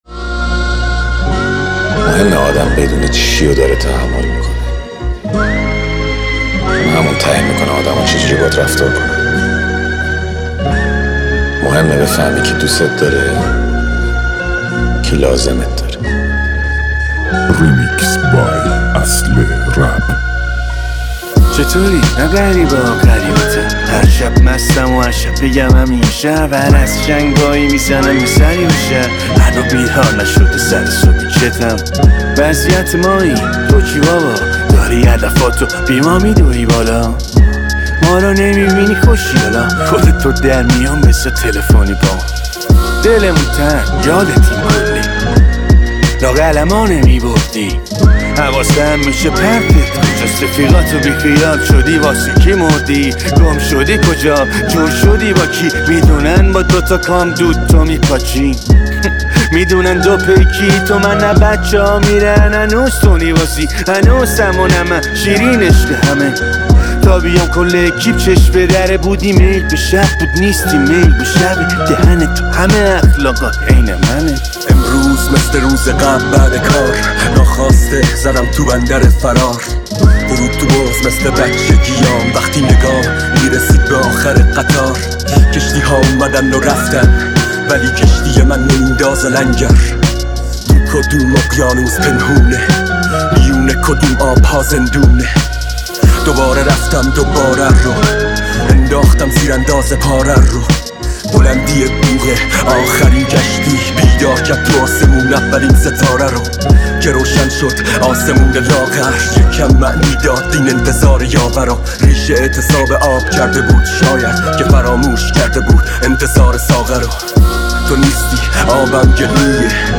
دانلود ریمیکس رپی